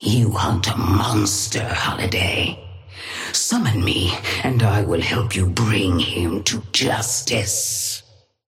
Sapphire Flame voice line - You hunt a monster, Holiday. Summon me and I will help you bring him to justice.
Patron_female_ally_astro_start_04.mp3